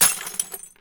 gem break.ogg